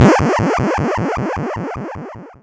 area_effect_a.wav